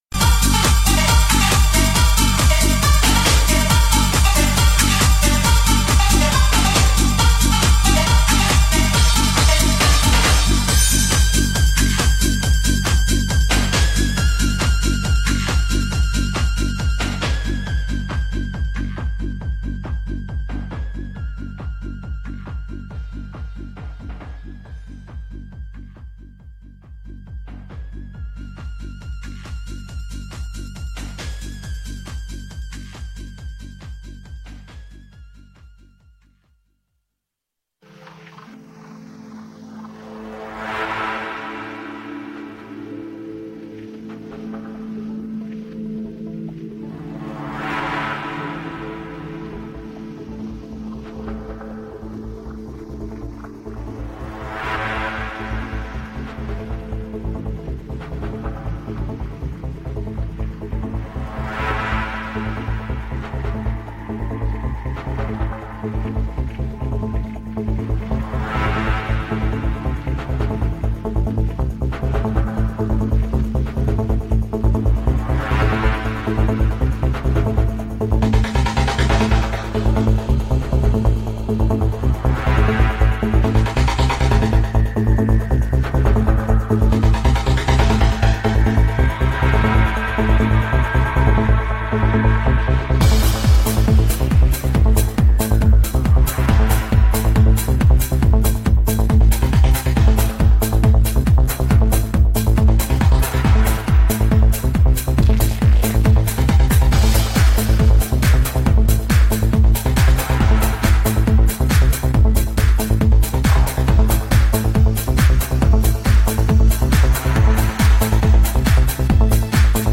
Also find other EDM
Liveset/DJ mix